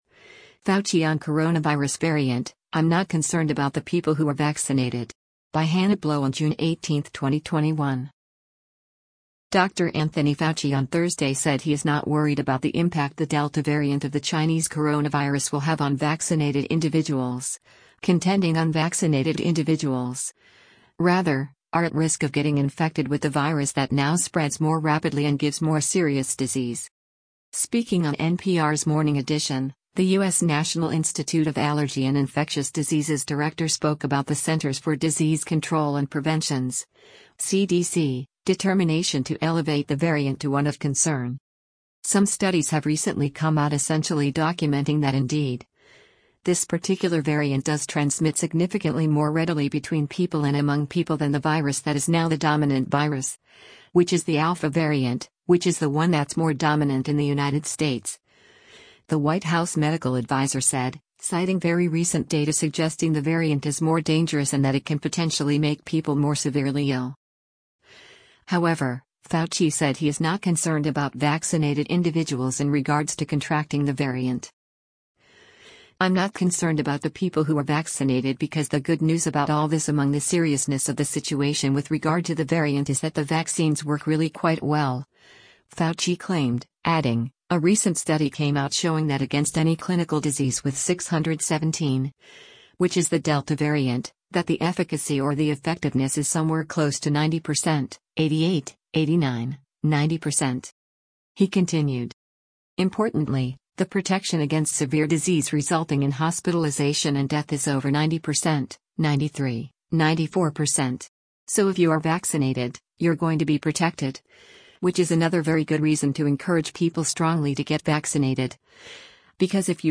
Speaking on NPR’s Morning Edition, the U.S. National Institute of Allergy and Infectious Diseases director spoke about the Centers for Disease Control and Prevention’s (CDC) determination to elevate the variant to one “of concern.”